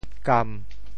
坩 部首拼音 部首 土 总笔划 8 部外笔划 5 普通话 gān 潮州发音 潮州 gam1 文 中文解释 坩 <名> 盛物的陶器 [earthenware] 侃少为寻阳县吏,尝监鱼梁,以一坩鮓遗母。